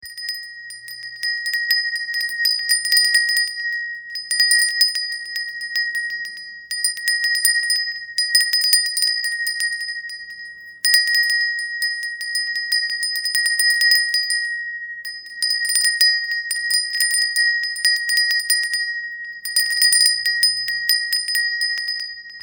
磁器風鈴は1つ１つ音色が違います。
風鈴の音色を確認できます
毬透かし風鈴 大秀窯